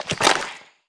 Bubblegun Hitplayer Sound Effect
bubblegun-hitplayer-1.mp3